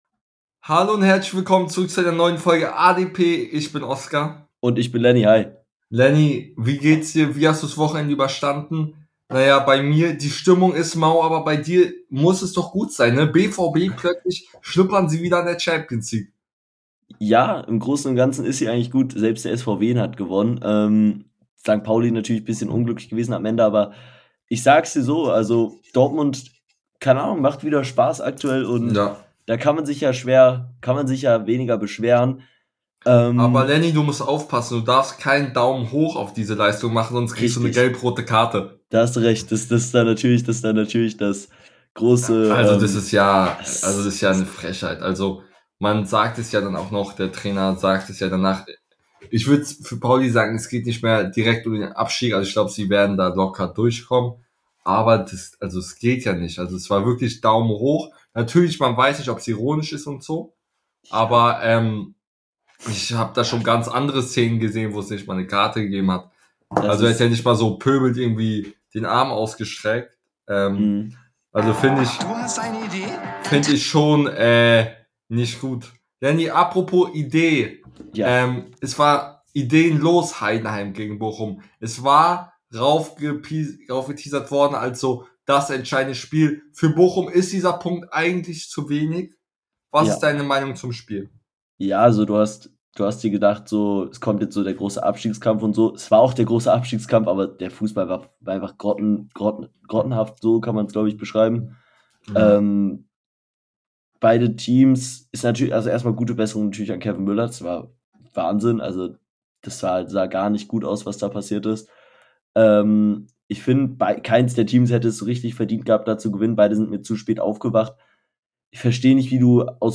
In der heutigen Folge reden die beiden Hosts über das sich zuspitzende Rennen um Europa , der Kampf um den Aufstieg und gegen den Abstieg und vieles mehr